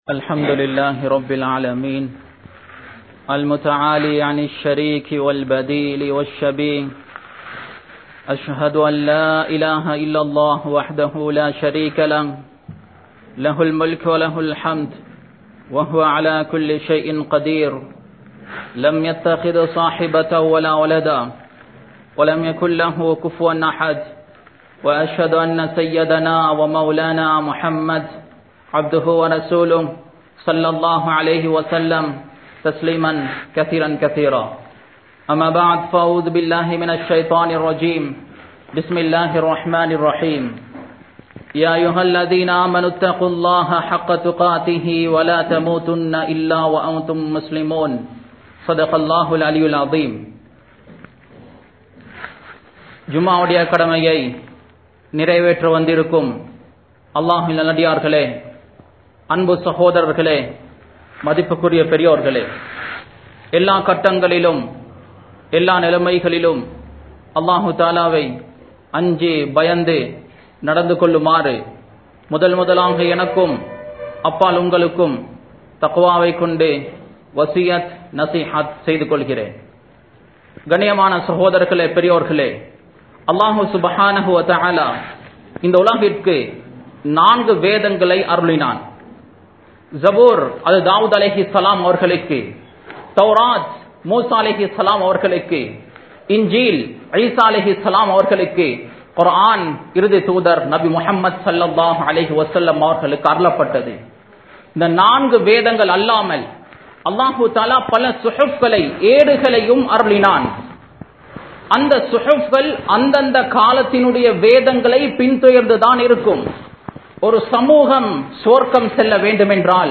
Tholuhaien Mukkiyaththuvam (தொழுகையின் முக்கியத்துவம்) | Audio Bayans | All Ceylon Muslim Youth Community | Addalaichenai